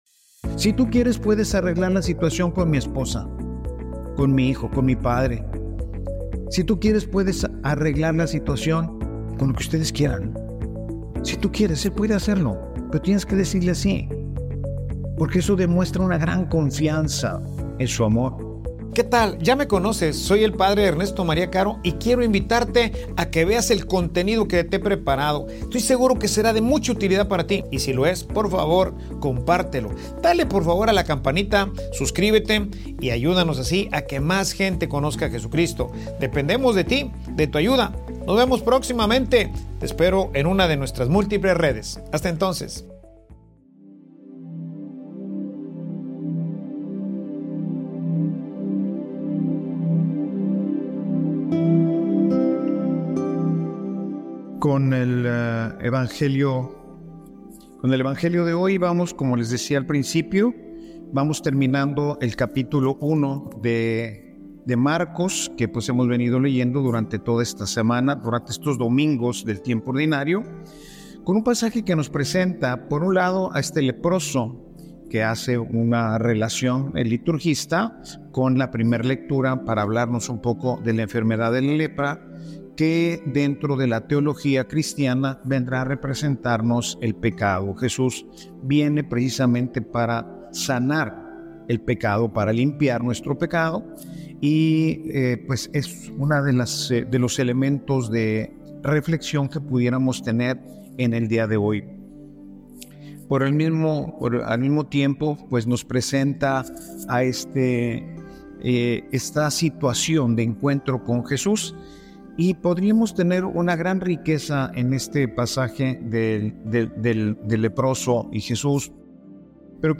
Homilia_Senor_confio_en_ti.mp3